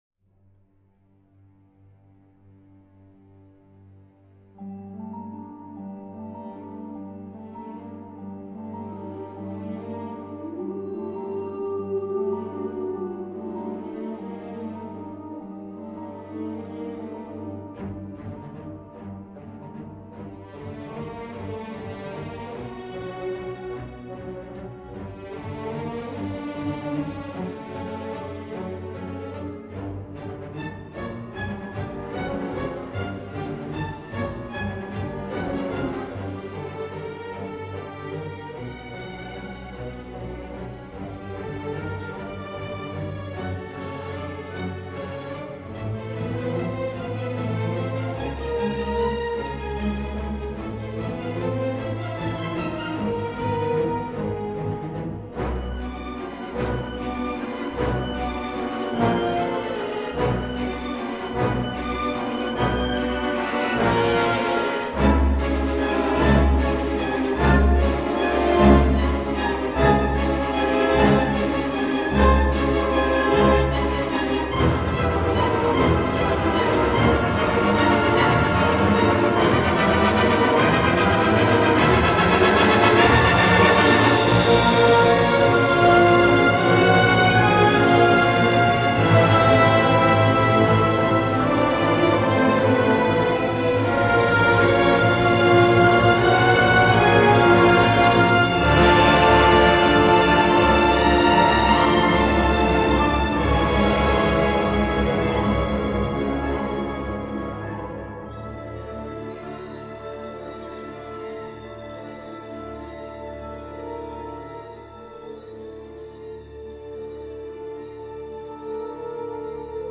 Jolie surprise, tendre et élégiaque.